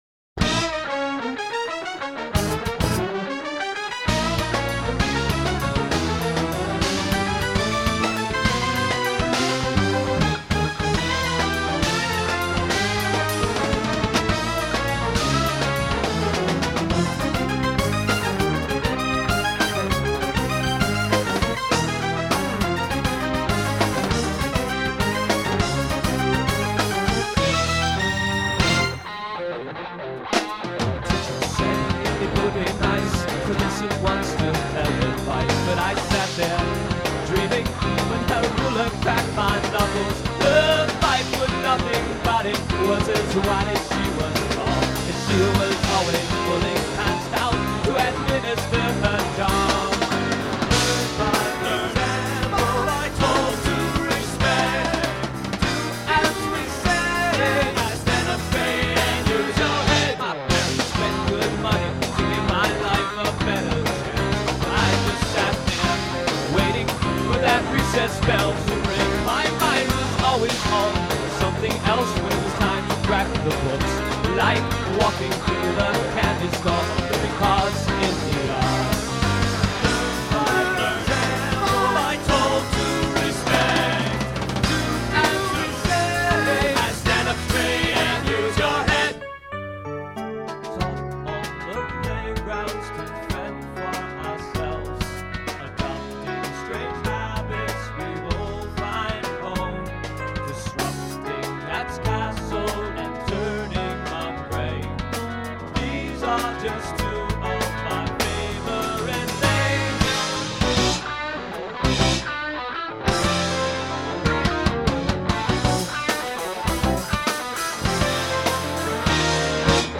keyboards, backing vocals
basses, midi pedals
guitars, lead and backing vocals
drums and percussion